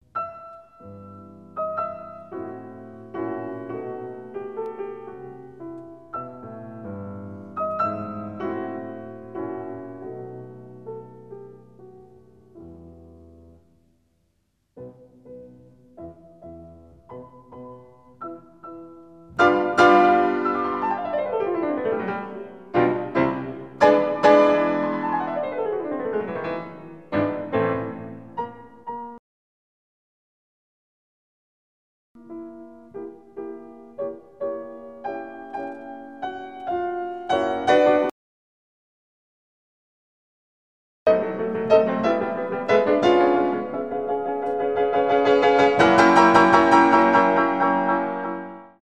инструментальные , пианино
классические